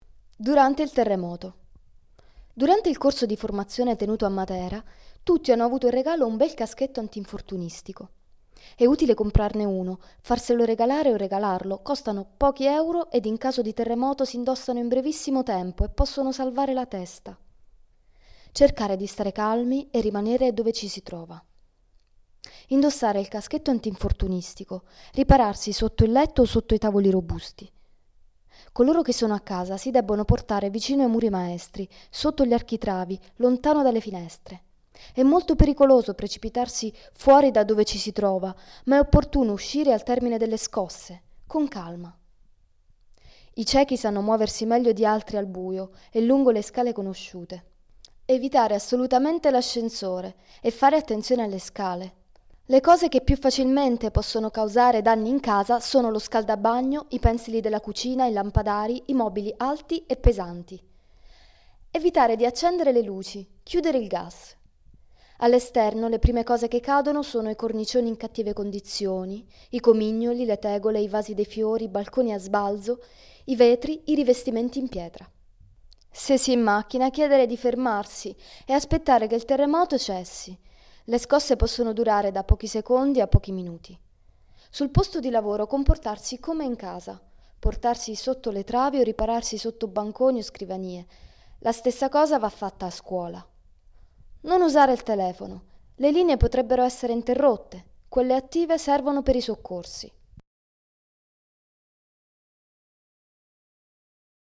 Versione Audio Libro